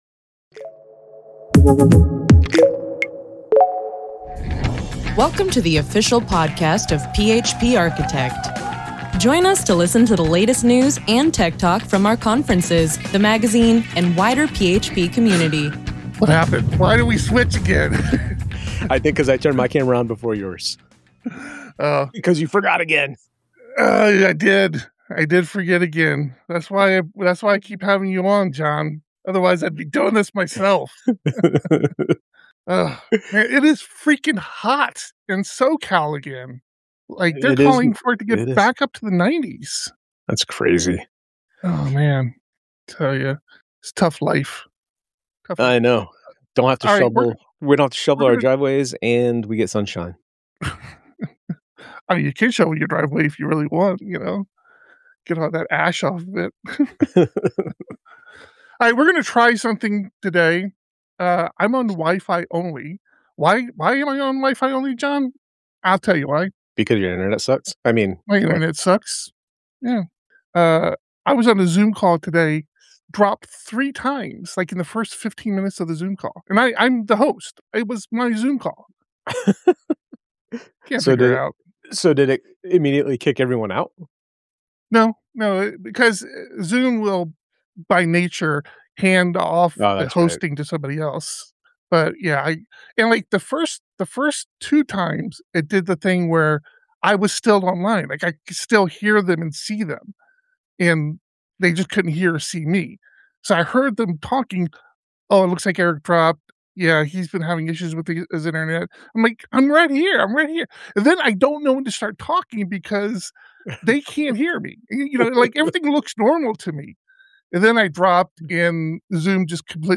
The PHP Podcast streams live, typically every Thursday at 3 PM PT.